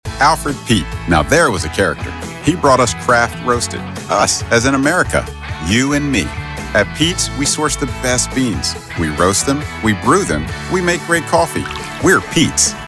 Commercial / Familiar / Friendly / Relatable
North American General American, North American US Mid-Atlantic
Middle Aged